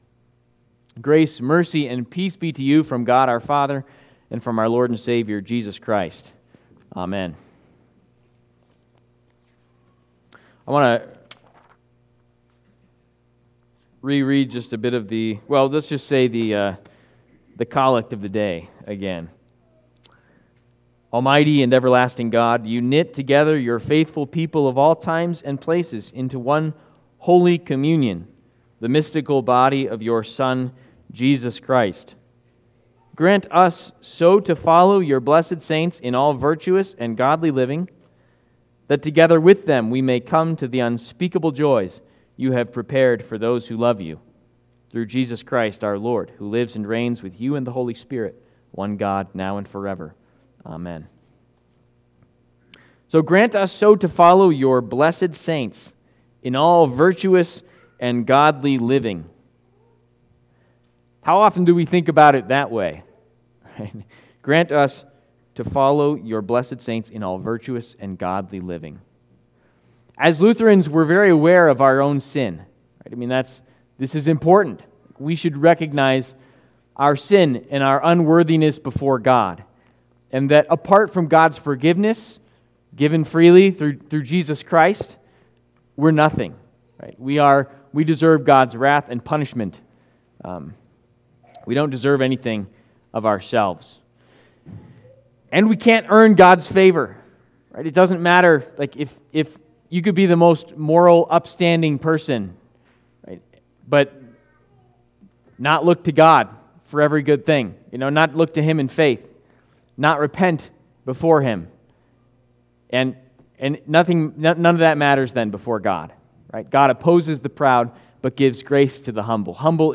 We Remember... All Saints Sunday